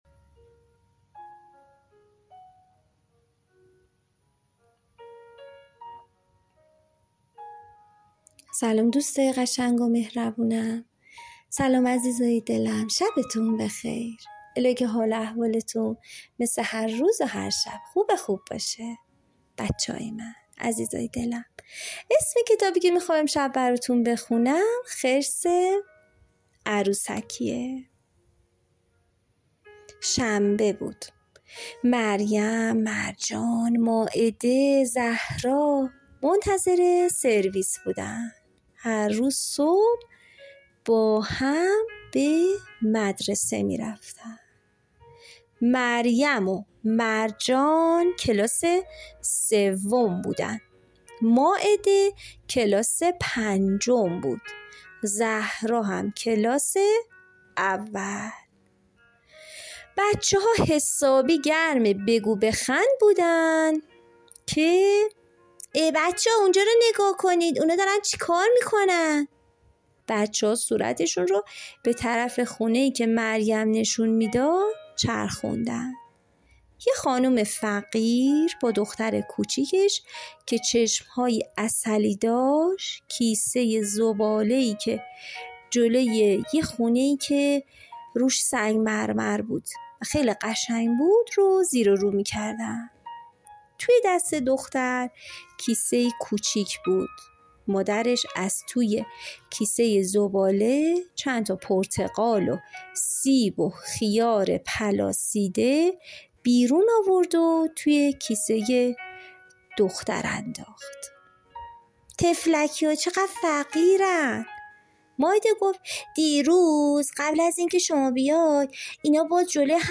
5 قصه صوتی کودکانه
قصه صوتی کودکان دیدگاه شما 186 بازدید